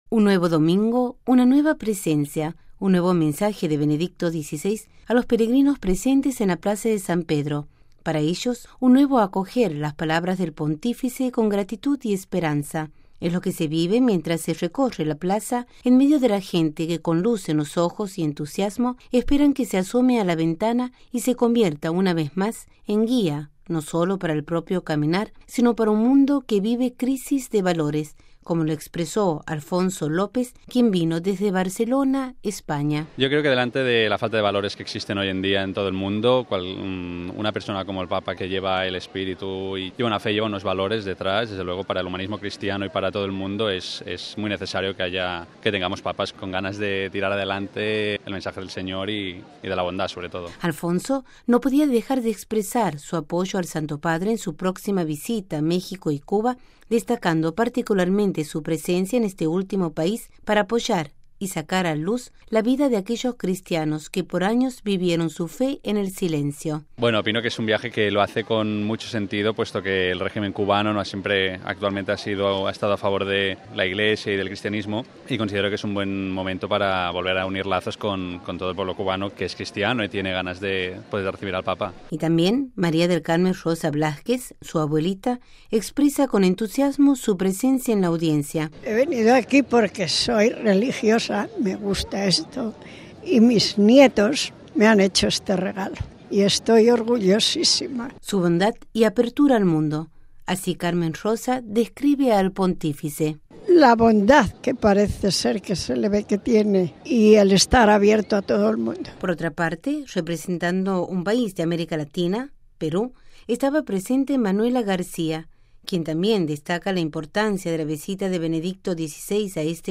Esta realidad puede, sin duda, describir la experiencia que realizan los peregrinos presentes en la plaza de San Pedro para acoger la presencia del Papa y tener luz en su propio caminar. En ellos se vive el anhelo de tener una guía espiritual, una presencia que orienta a los valores del evangelio y que acompaña e ilumina la propia realidad.